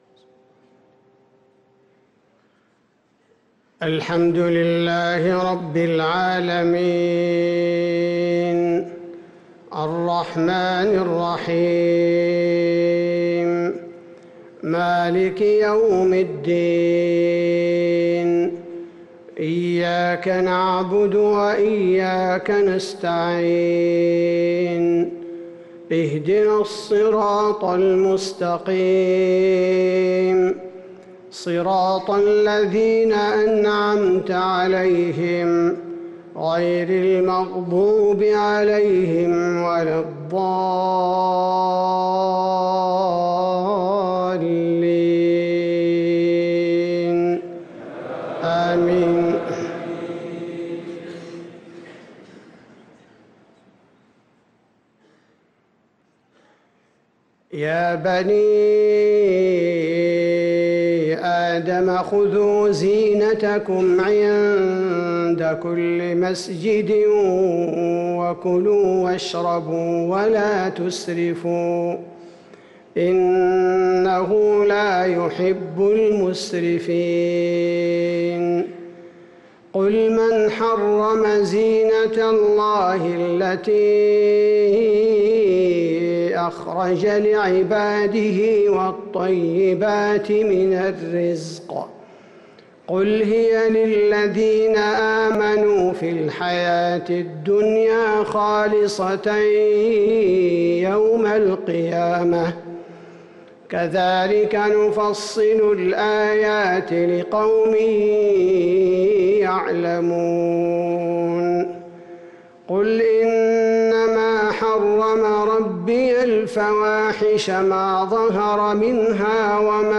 صلاة المغرب للقارئ عبدالباري الثبيتي 17 ربيع الآخر 1445 هـ